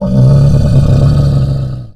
MONSTER_Growl_Medium_22_mono.wav